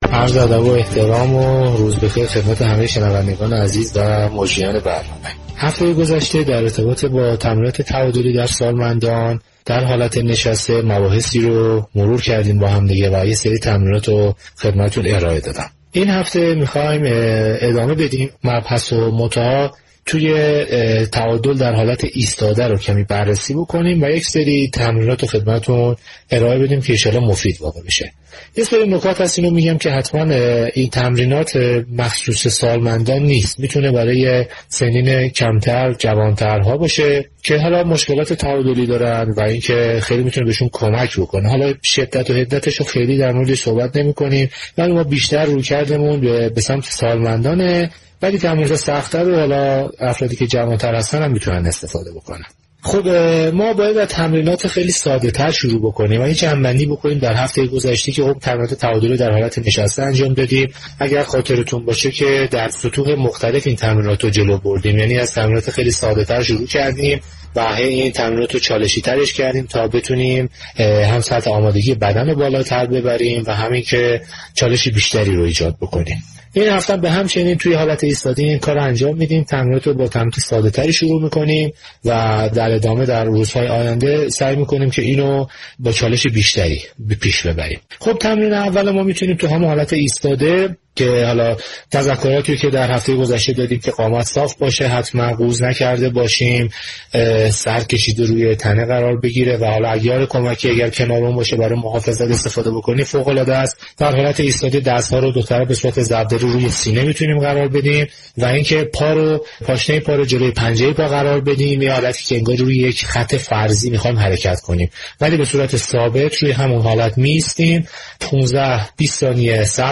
شما می توانید از طریق فایل صوتی پیوست شنونده بخشی از برنامه "گلخونه" رادیو ورزش كه به توضیح درباره چگونگی اجرای تمرین برای حفظ تعادل در حالت ایستاده برای سالمندان می پردازد؛ باشید.